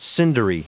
Prononciation du mot cindery en anglais (fichier audio)
Prononciation du mot : cindery